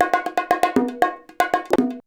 119BONG11.wav